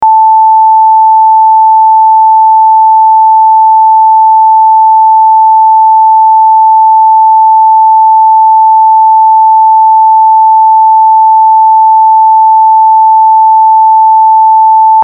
880Hzの音声を-0.1dBにノーマライズした音声です。YouTubeの880Hz音声よりも音量が大きくなり、DSに録音しやすいと思います。
chatot-880hz.mp3